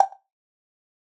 click-close.ogg